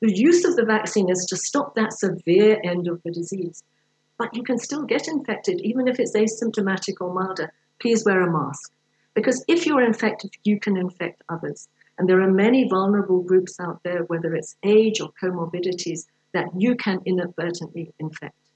During a parliamentary briefing this week, Rees said getting vaccinated would also help reduce pressure on the country’s healthcare system.